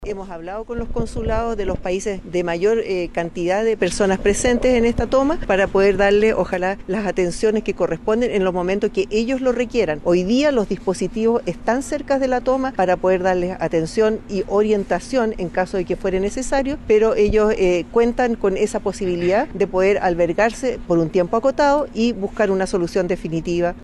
La alcaldesa de la comuna, Carmen Castillo, declaró que ha tenido conversaciones con los consulados de los países de la mayor cantidad de las personas presentes en la toma, para darles las atenciones correspondientes.